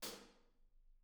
R_B Hi-Hat 01 - Room.wav